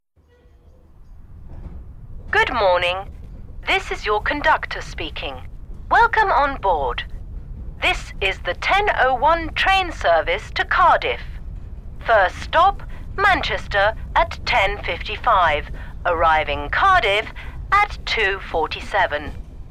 • Activités : écoute d’annonces ferroviaires, compréhension orale, repérage de l’heure et de la destination des trains entendus